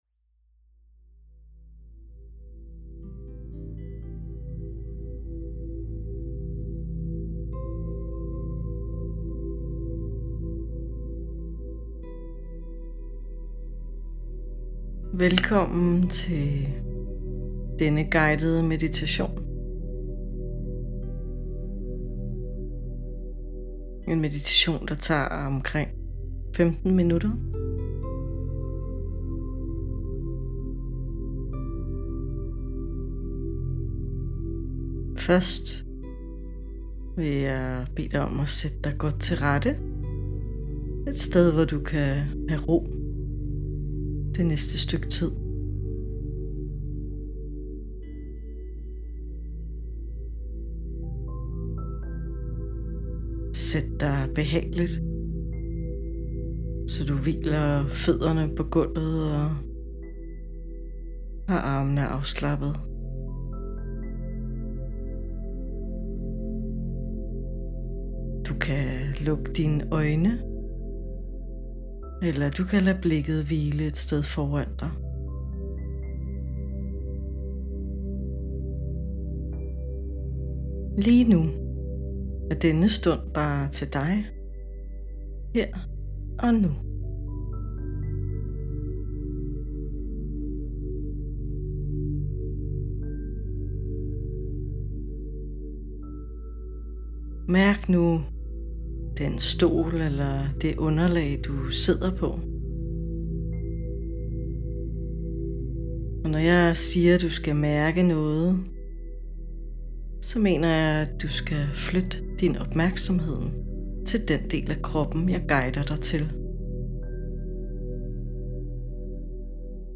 Meditation til unge
Måske har du prøvet det – at lukke øjnene og lytte til en rolig stemme, der guider dig gennem vejrtrækning, billeder eller bare stilhed.
Meditation-Unge-15-min.mp3